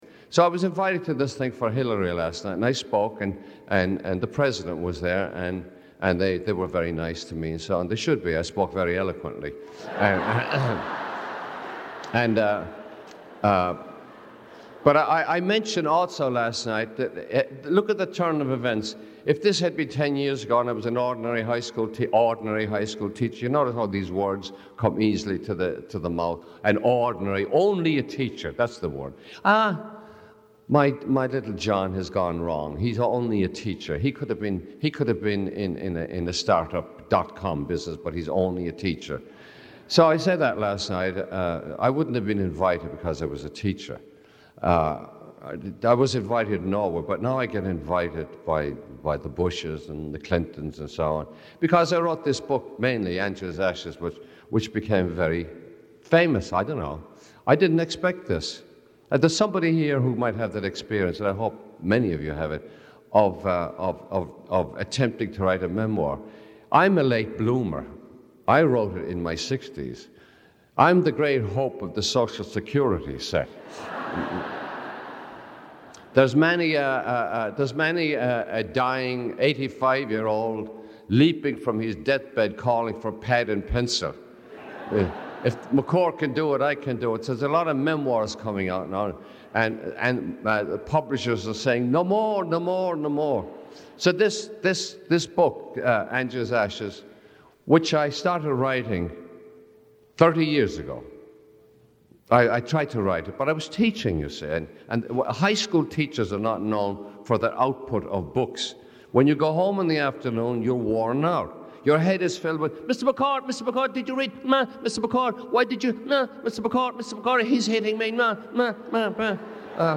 Lecture Title